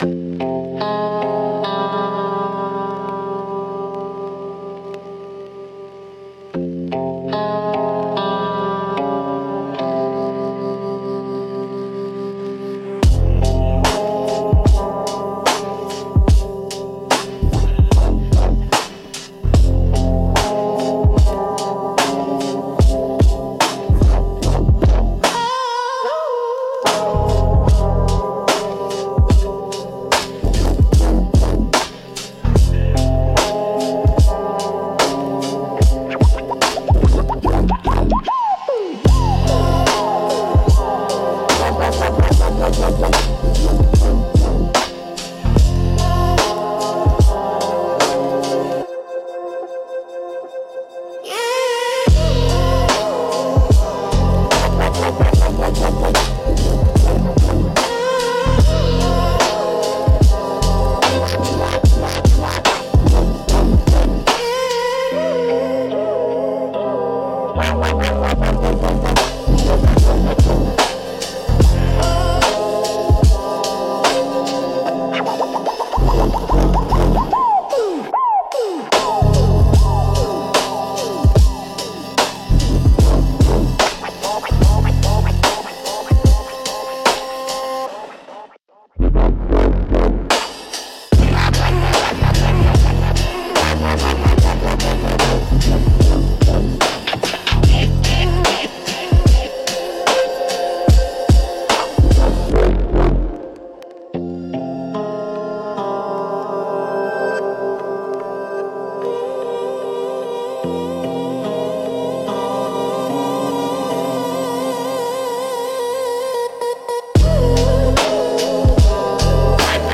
Instrumental - Midnight Choke & Cut